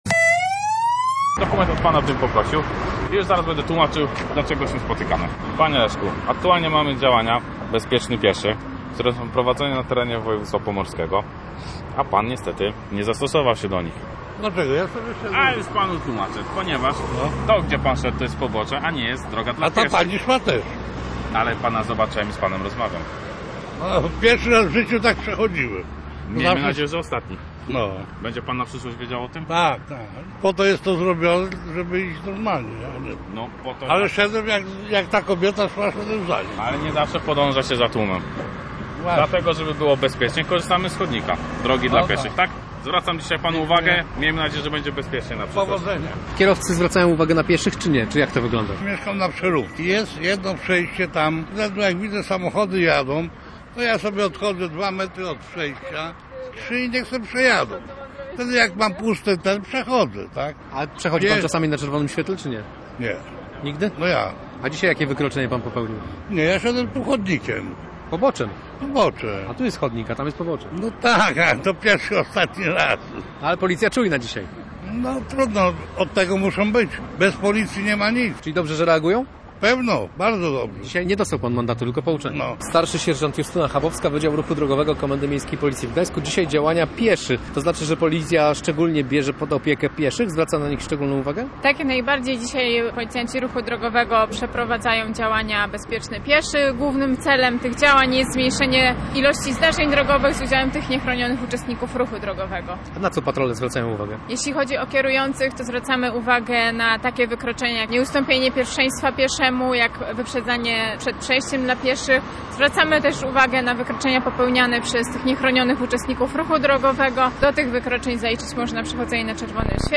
Policjantom gdańskiej drogówki podczas akcji „Bezpieczny pieszy” towarzyszył z mikrofonem